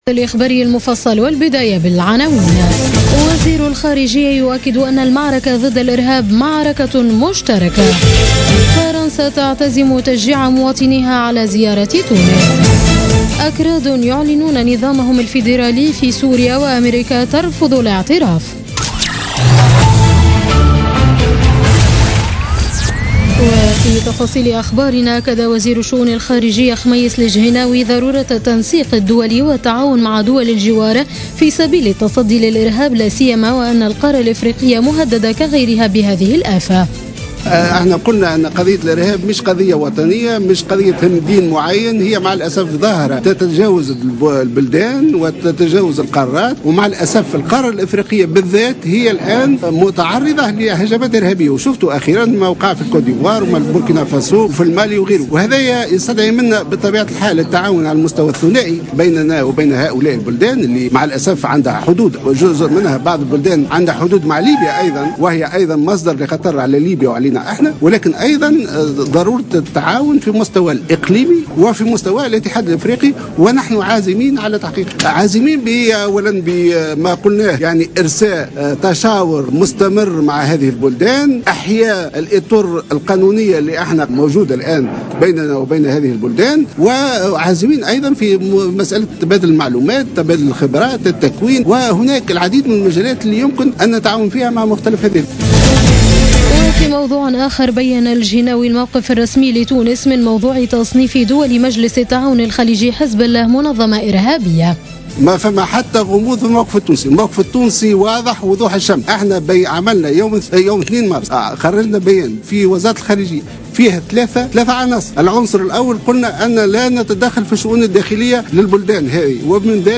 نشرة أخبار منتصف الليل ليوم الجمعة 18 مارس 2016